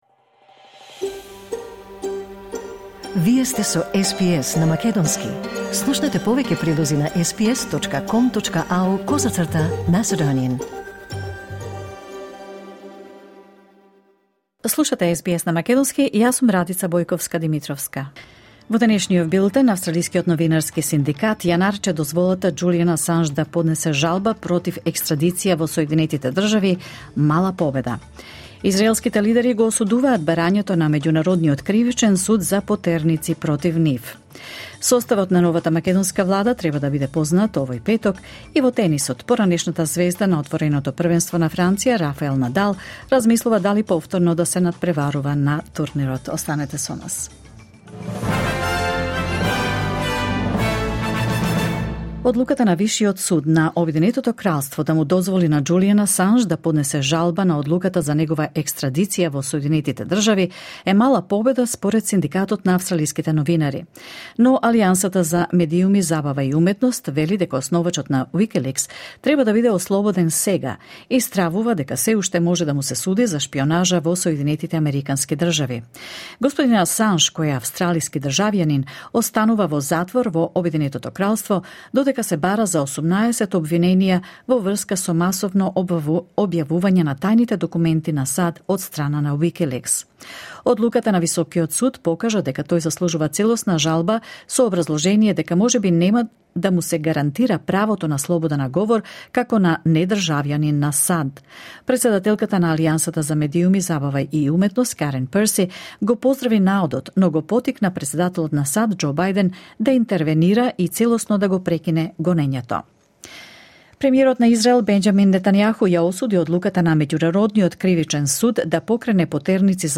Вести на СБС на македонски 21 мај 2024